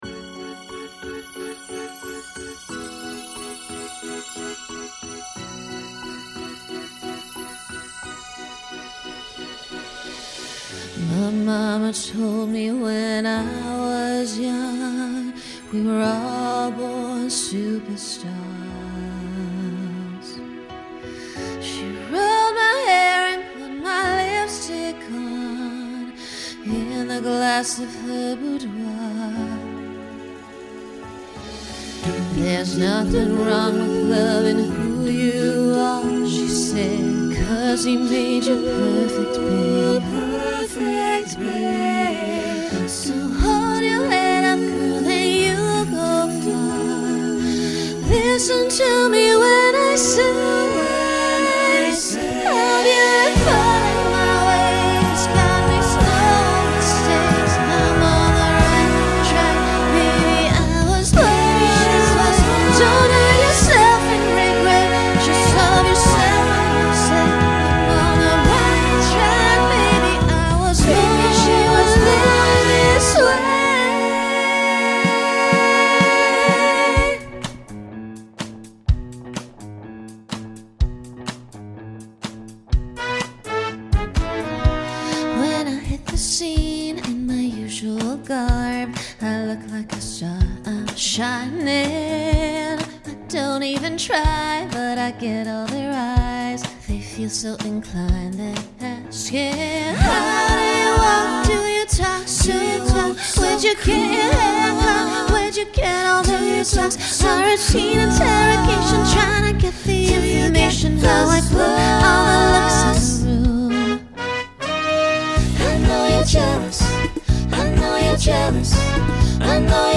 Genre Pop/Dance , Rock
Voicing SATB